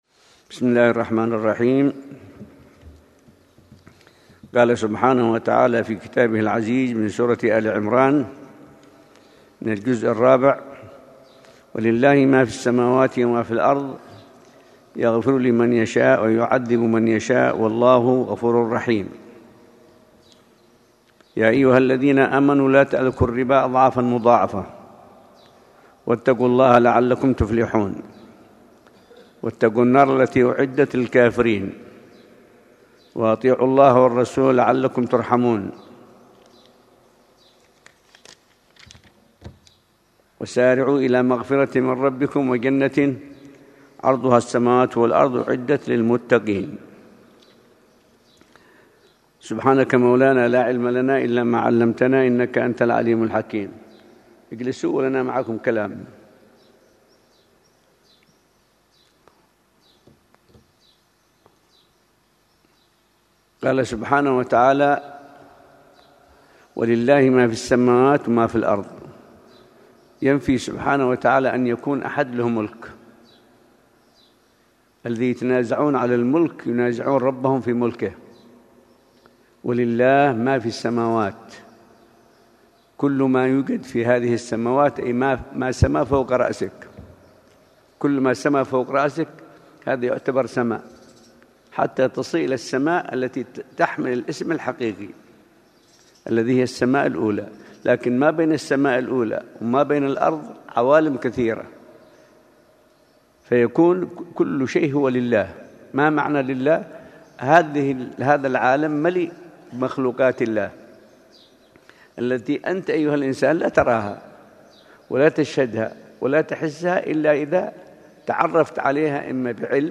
قال تعالى في سورة آل عمران: درس فجر الأربعاء ٥ شعبان ١٤٤٠ه‍ بمسجد الأحمدين بشعب الإمام المهاجر – الحسيسة – حضرموت